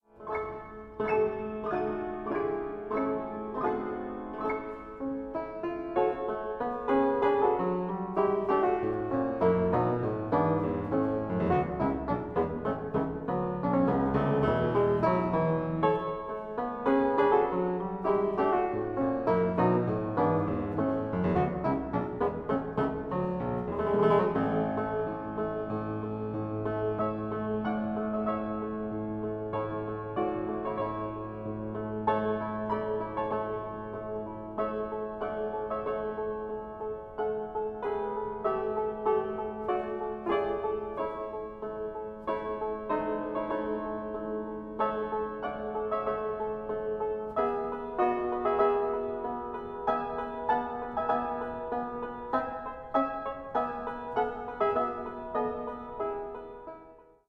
Allegretto